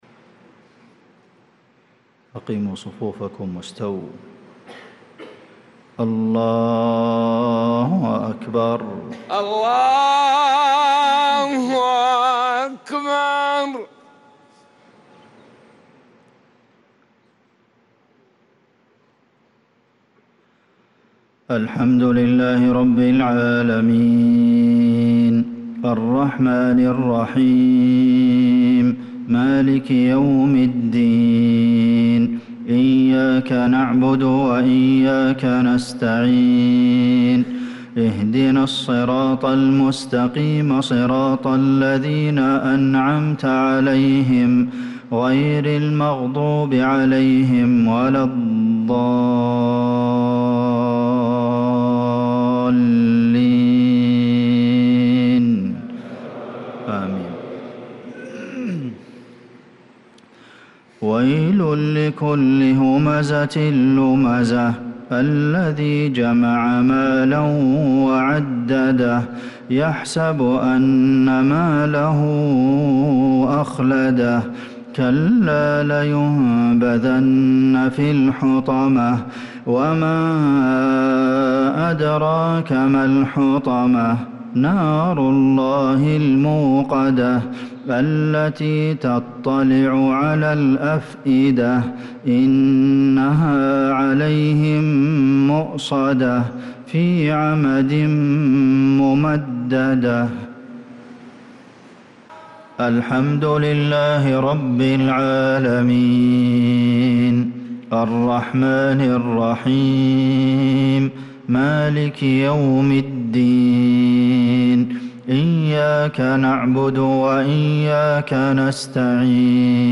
صلاة المغرب للقارئ عبدالمحسن القاسم 28 محرم 1446 هـ
تِلَاوَات الْحَرَمَيْن .